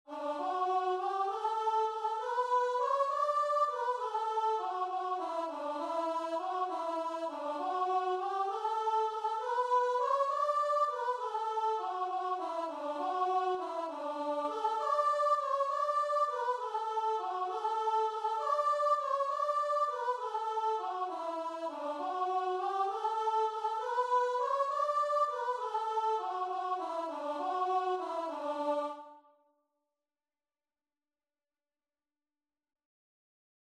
Christian
6/8 (View more 6/8 Music)
Classical (View more Classical Guitar and Vocal Music)